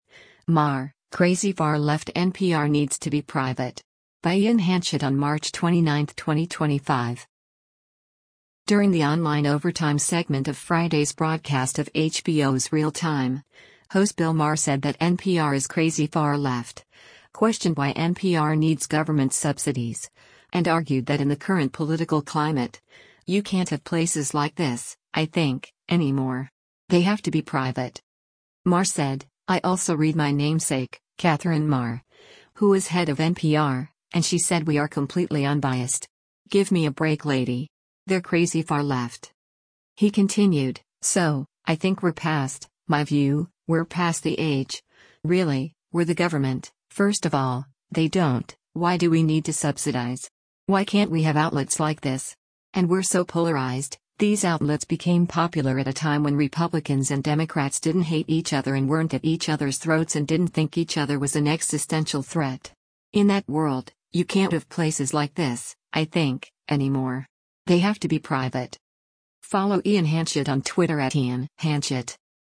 During the online “Overtime” segment of Friday’s broadcast of HBO’s “Real Time,” host Bill Maher said that NPR is “crazy far-left”, questioned why NPR needs government subsidies, and argued that in the current political climate, “you can’t have places like this, I think, anymore. They have to be private.”